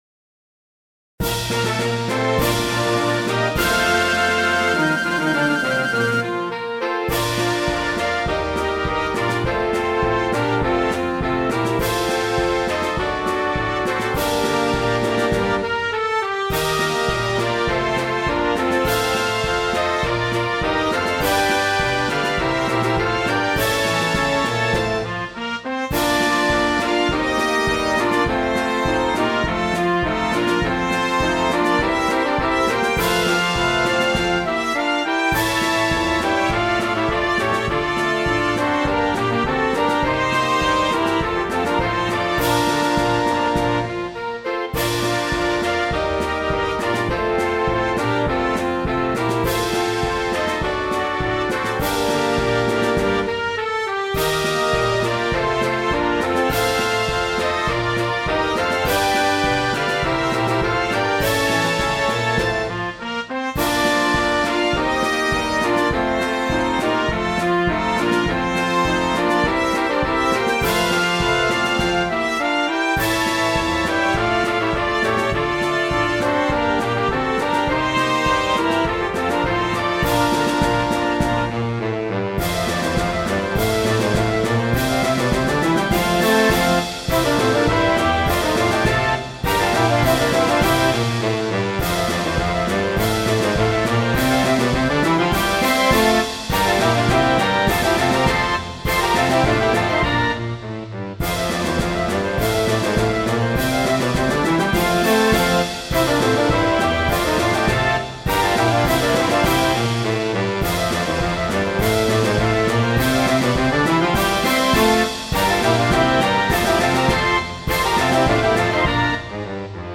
Sei marce per banda scaricabili gratuitamente.